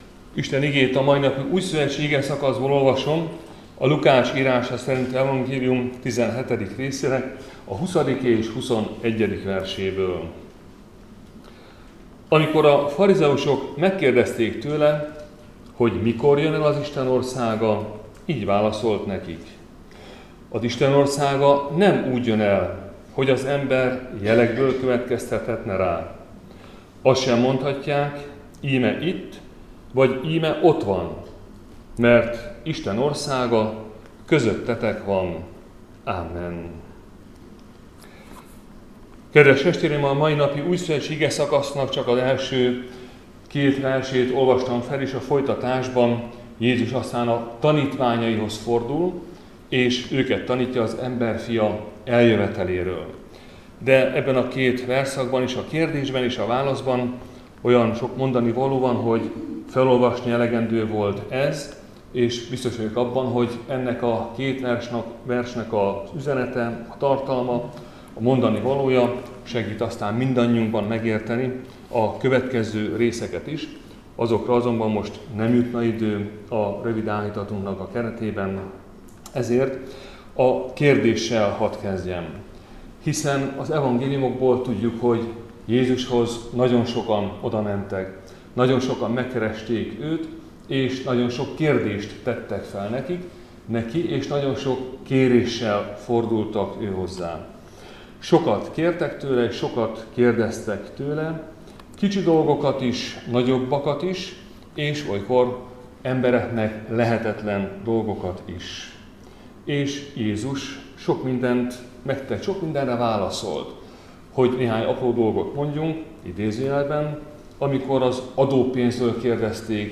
Áhítat, 2025. március 18.